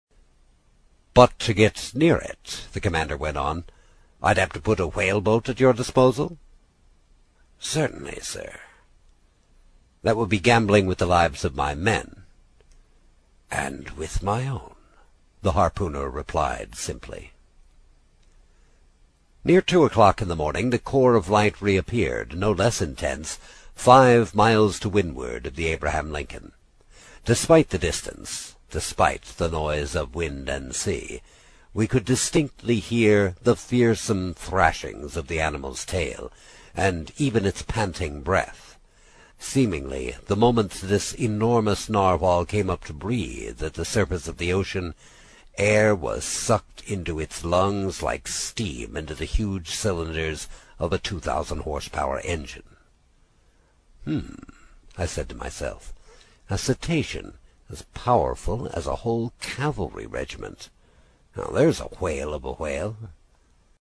英语听书《海底两万里》第65期 第6章 开足马力(7) 听力文件下载—在线英语听力室
在线英语听力室英语听书《海底两万里》第65期 第6章 开足马力(7)的听力文件下载,《海底两万里》中英双语有声读物附MP3下载